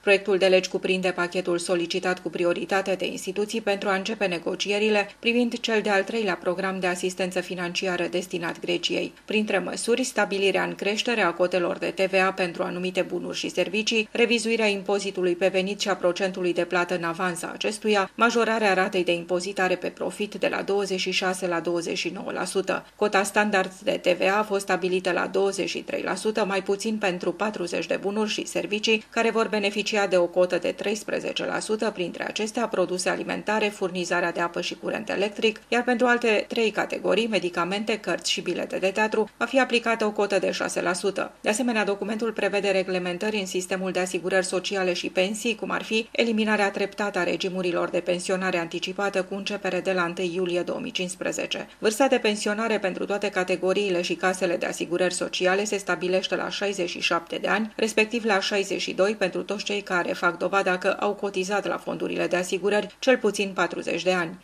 Corespondenta RRA